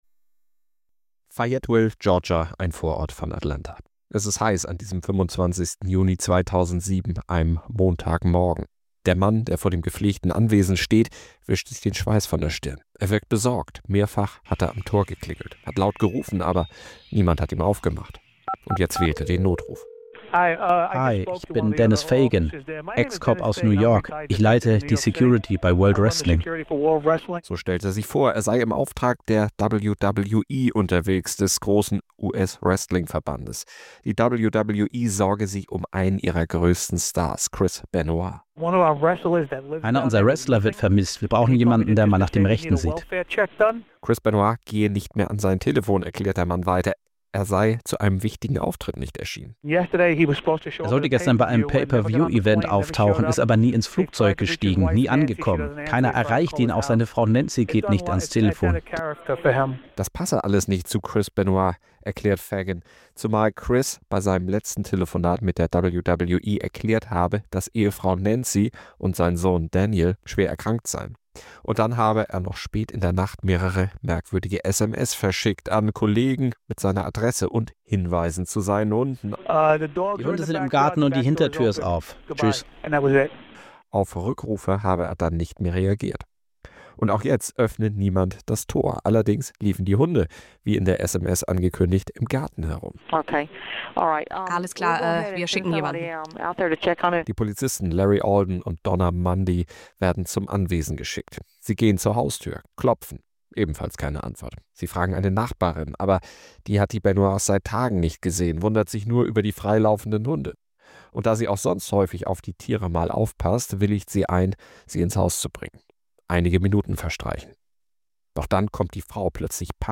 Die Voiceover der O-Töne in dieser Folge wurden mithilfe künstlicher Intelligenz erstellt.